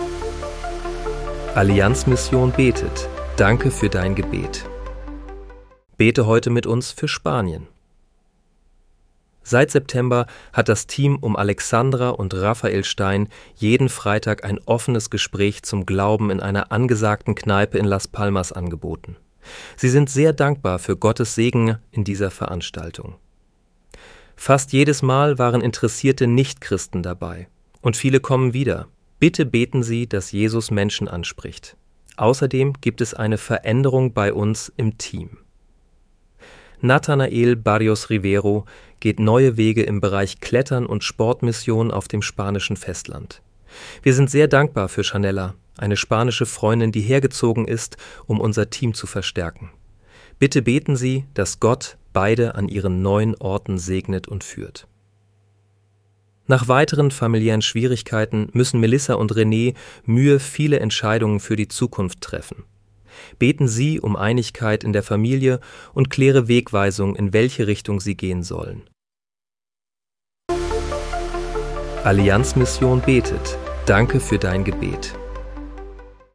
Bete am 27. Dezember 2025 mit uns für Spanien. (KI-generiert mit